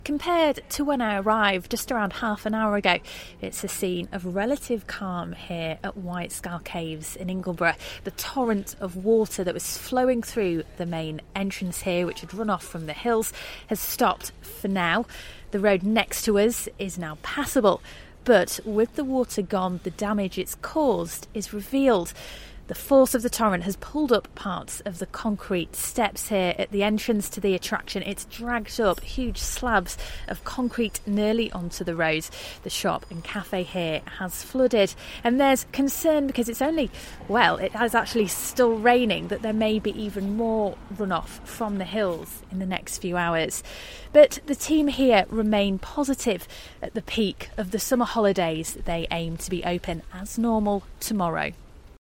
reports from Ingleton as flooding closes White Scar Cave